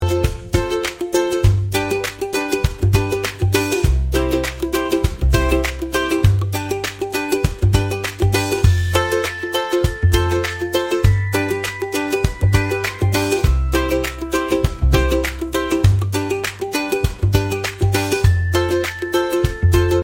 纯音乐
advertisement jingle music, ukulele, hawaiian, tropical, island, happy, upbeat